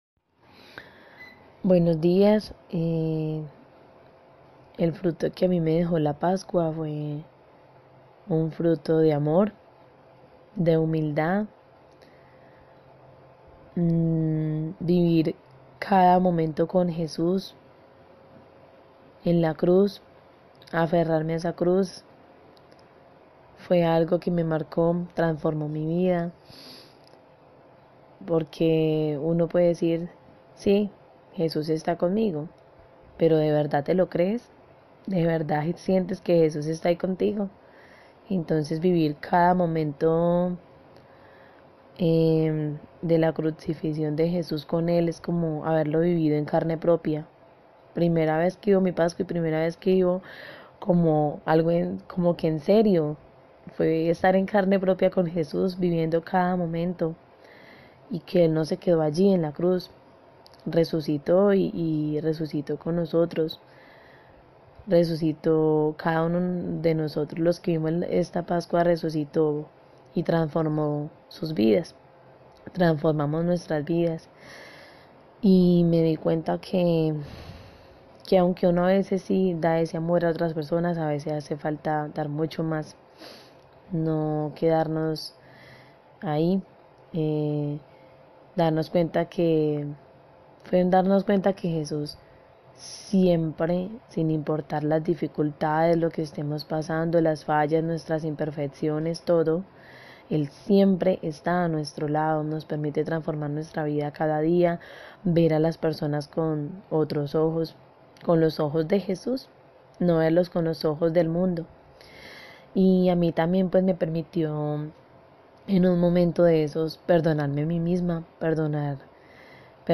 Este es uno de nuestros testimonios:
TestimonioFrutoPascua.mp3